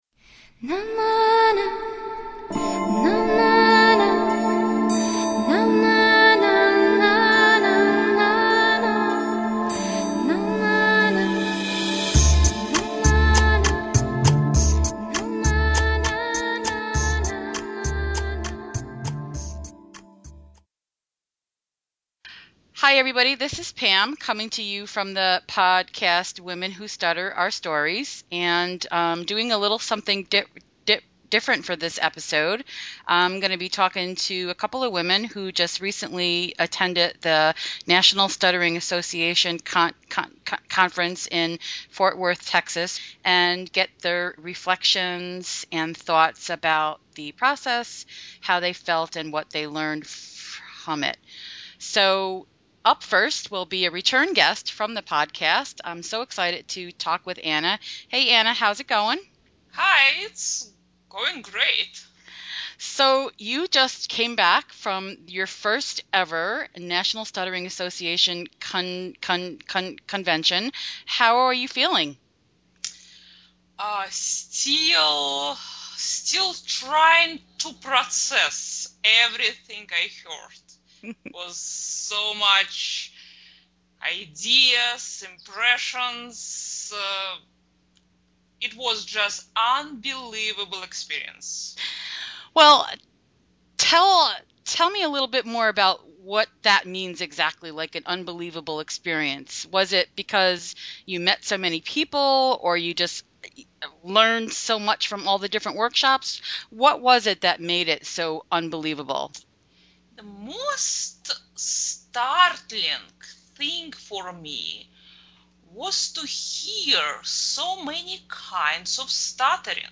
This is the first time I have recorded with several women at different times and attempted (notice I say attempted) to string the clips together into one cohesive episode.
This episode features three women who all were previous guests. We chat about their experiences at the recent National Stuttering Association conference.